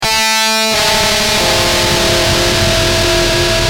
Screamb7.wav